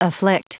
Prononciation du mot afflict en anglais (fichier audio)
Prononciation du mot : afflict